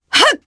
Demia-Vox_Attack1_jp_b.wav